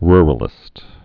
(rrə-lĭst)